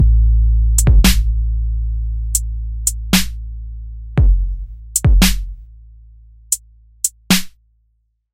dub Step " Dew Void Dub Step 138bpm
描述：138bpm的Dubstep主节拍，用reason、redrum和NN19编程，Hits Taken from various Collected Sample Pack and cuts
标签： 低音 休息 dubstep的 沉重的 因此 REDRUM scailing 稀疏 三胞胎
声道立体声